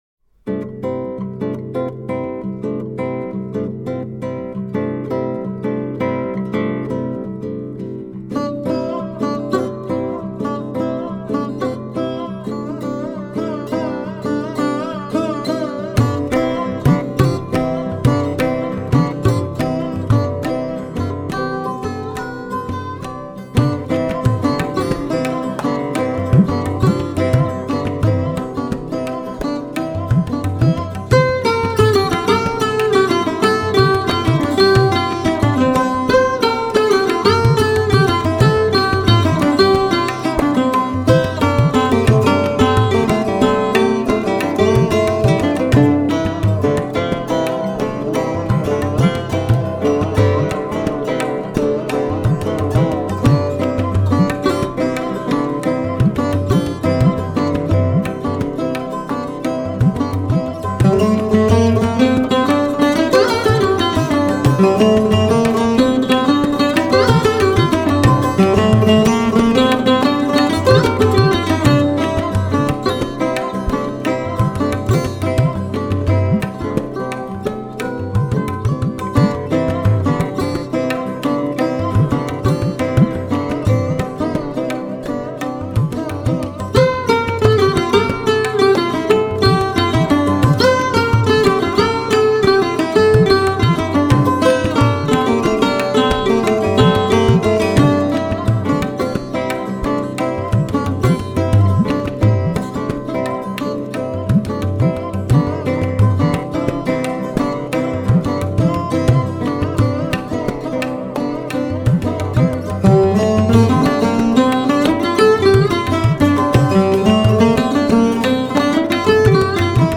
World / crossing of Indian and classical music.
classic guitarist
Indian sarod player
They are partly accompanied by tablas and vocals.